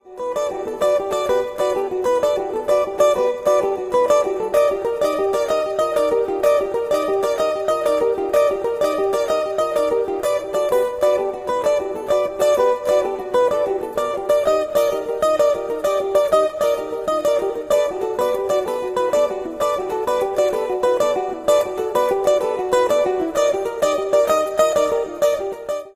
guitar sample